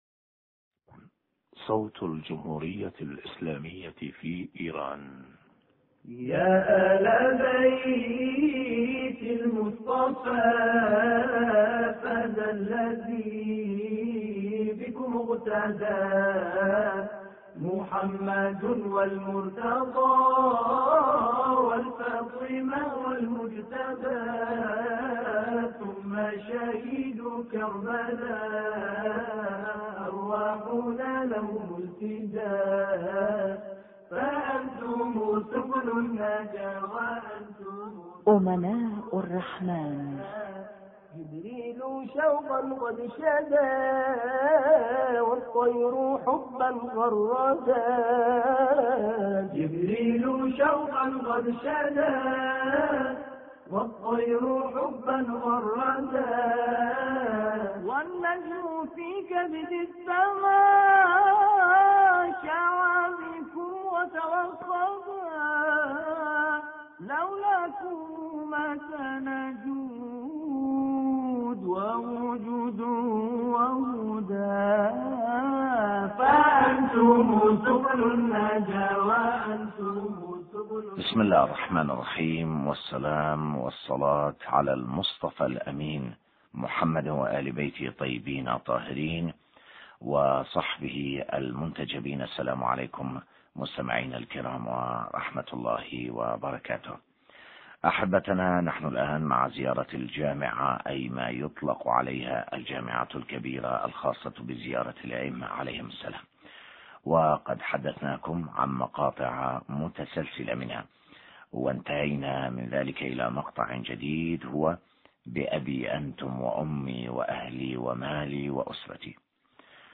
حوار
في حوار زميلنا معه عبر الهاتف